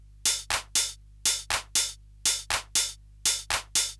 groove_hihatclap.wav